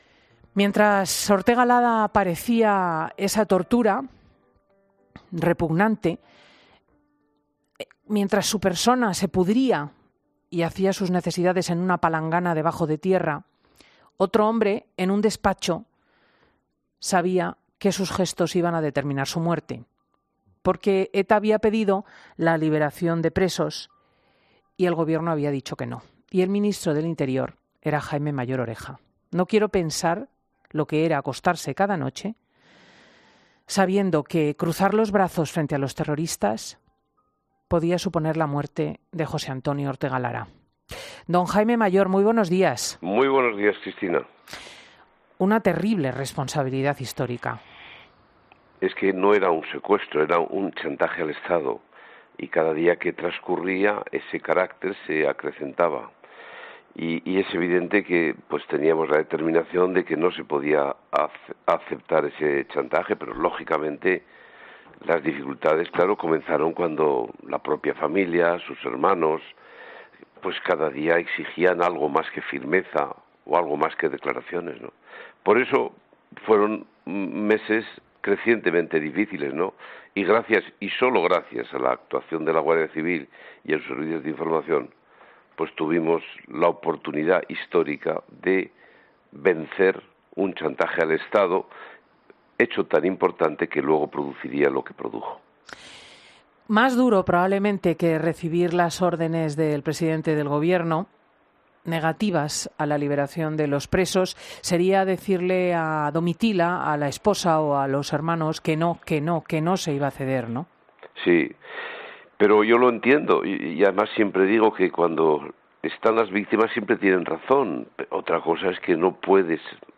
Entrevista a Jaime Mayor Oreja, ex ministro de Interior